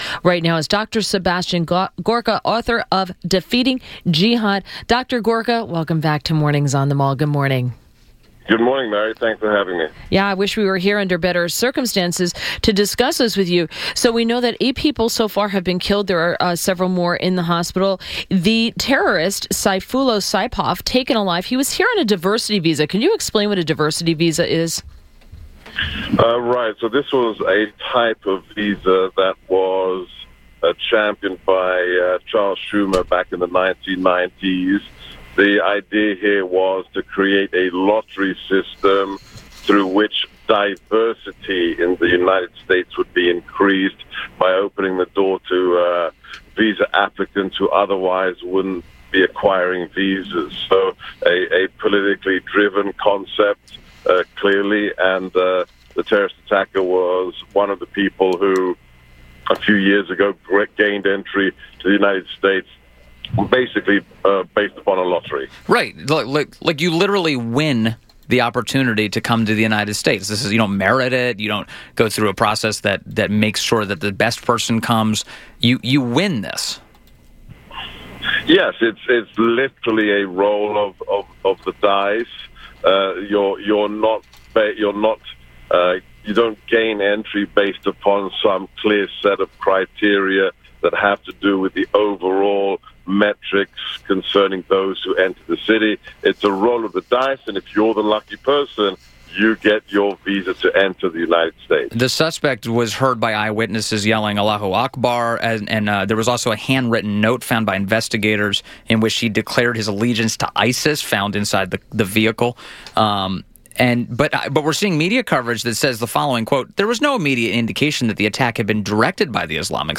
WMAL Interview - DR. SEBASTIAN GORKA - 11.01.17